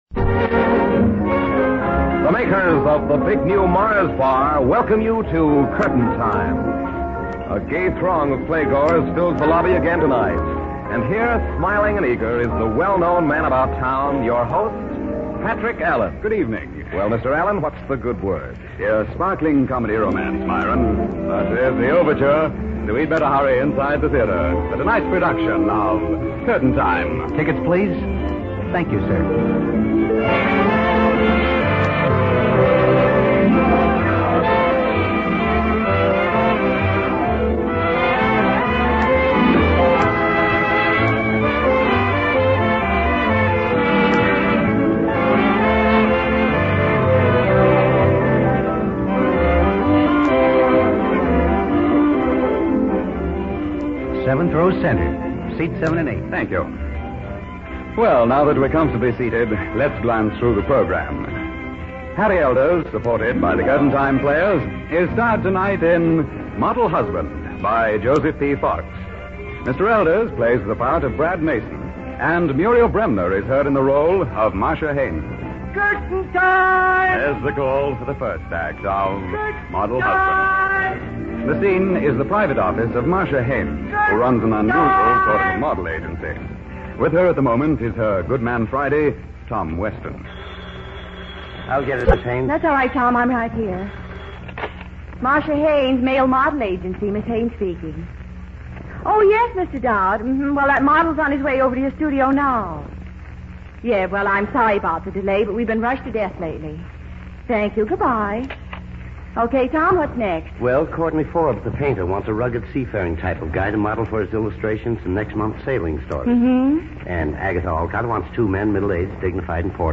Curtain Time was a popular American radio anthology program that aired during the Golden Age of Radio. It was known for its romantic dramas and its unique presentation style that aimed to recreate the atmosphere of attending a live theater performance.Broadcast History: 1938-1939: The show first aired on the Mutual Broadcasting System from Chicago. 1945-1950: It had a much more successful run on ABC and NBC, gaining a wider audience and greater popularity. Format and Features: "Theater Atmosphere": The show used sound effects and announcements to evoke the feeling of being in a theater, with an announcer acting as an usher and reminding listeners to have their tickets ready.